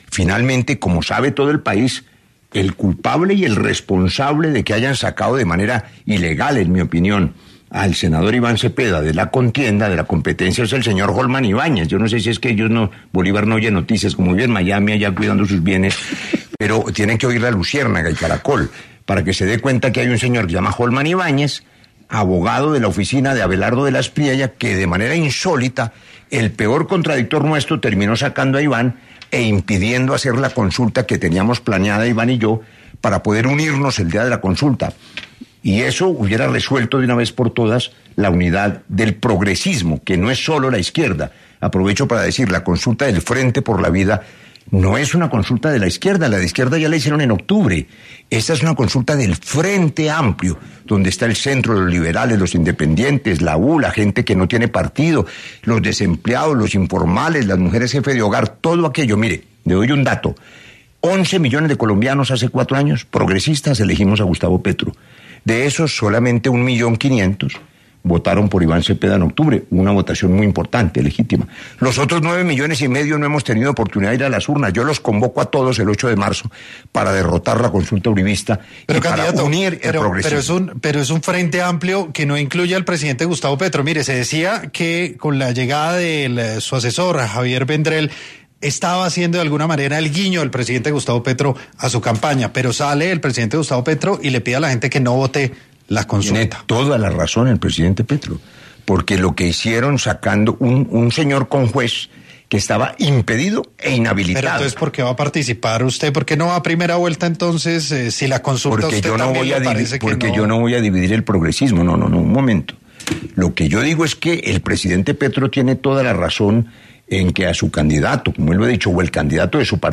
En Sin Anestesia de La Luciérnaga estuvo el precandidato presidencial Roy Barreras, quien habló sobre la consulta del ‘Frente por la Vida’.